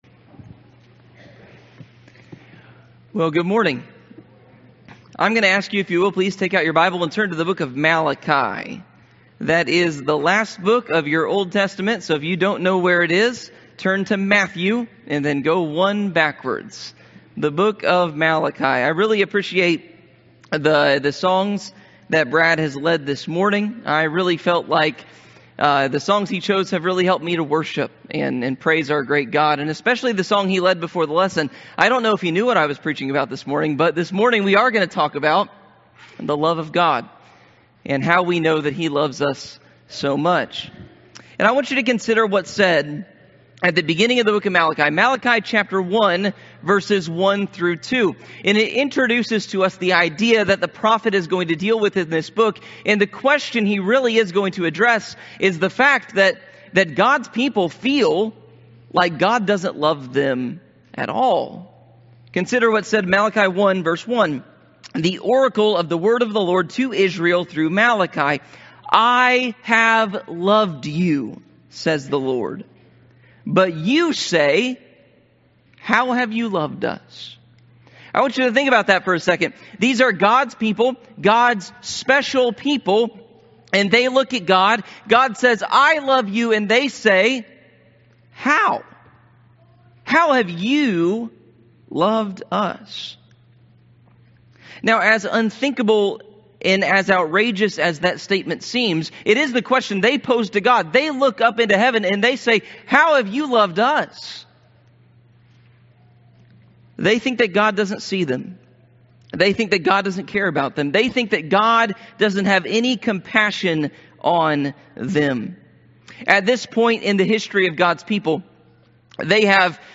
Sermons How Have You Loved Us?